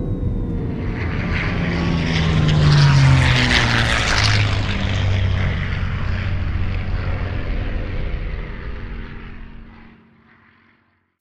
jetBeginLand.wav